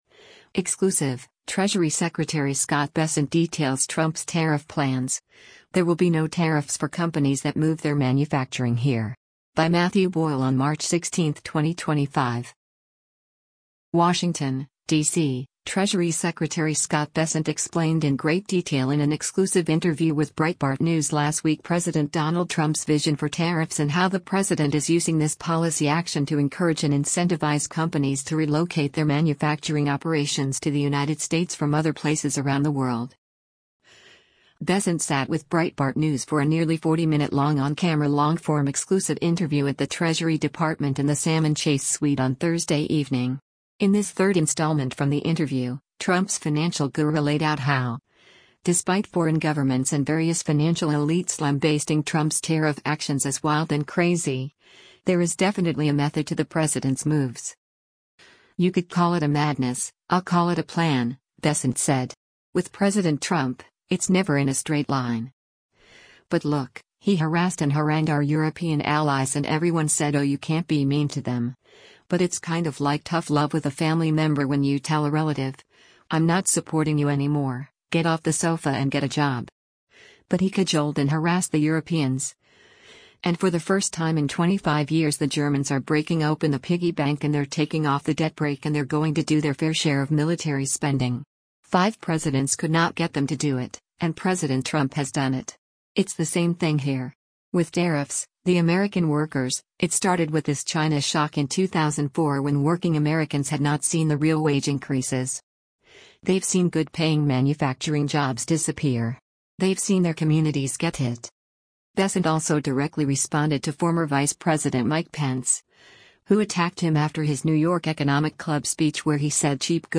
Bessent sat with Breitbart News for a nearly 40-minute-long on-camera long-form exclusive interview at the Treasury Department in the Salmon Chase Suite on Thursday evening.